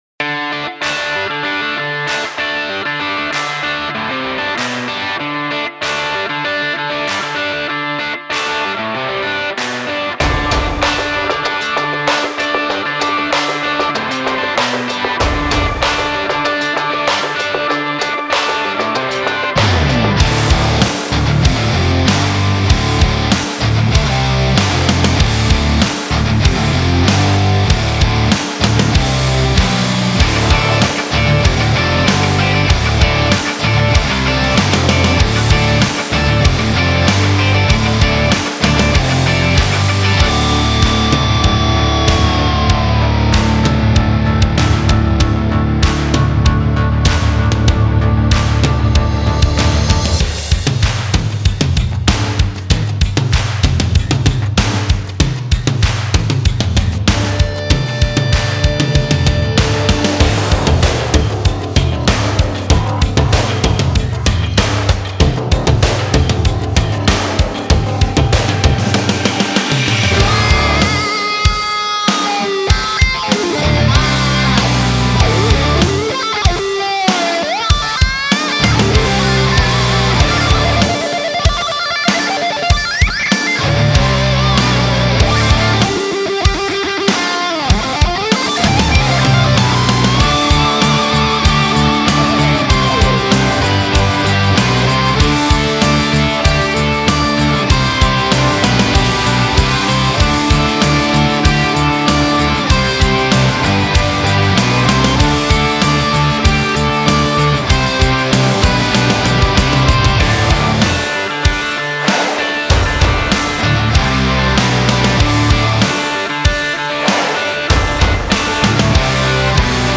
Modern Rock (Glam?)